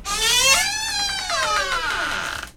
open.ogg